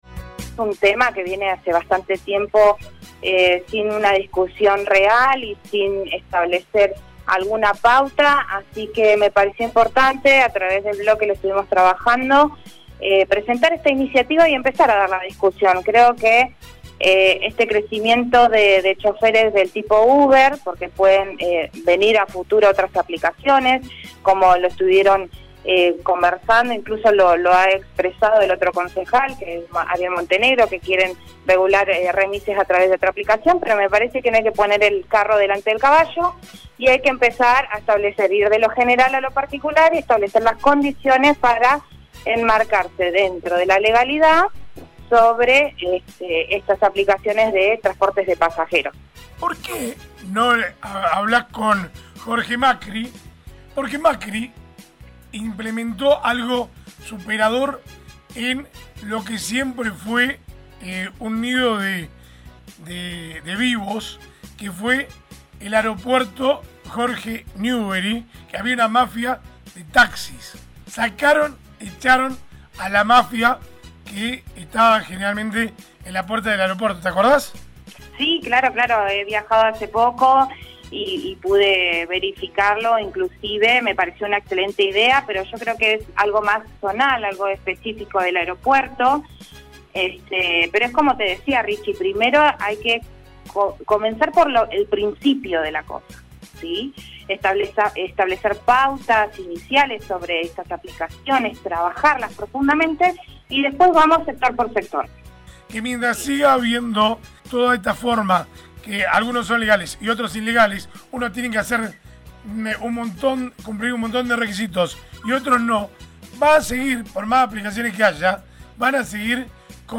Así lo explicaba en el aire de LA MAÑANA DE HOY: